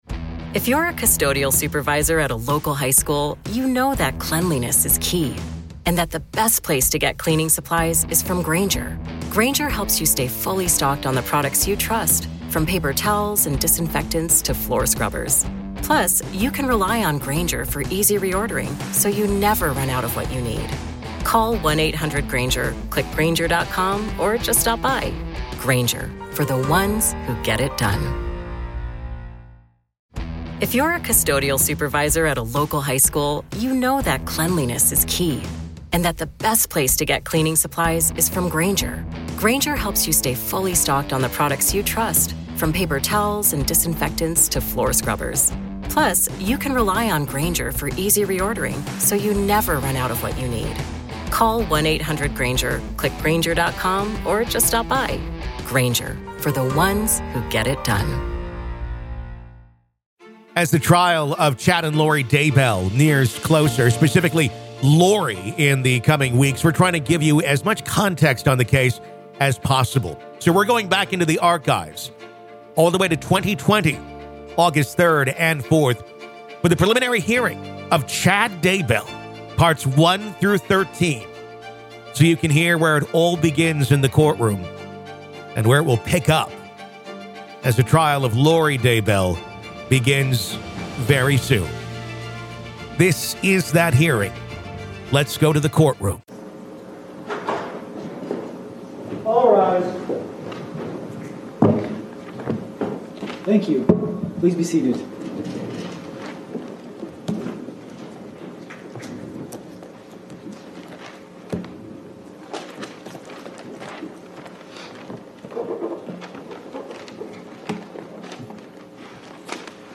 Listen To The Full Preliminary Hearing Of Chad Daybell, Part 5
This is the complete preliminary hearing of Chad Daybell, originally recorded August 3rd and 4th of 2020.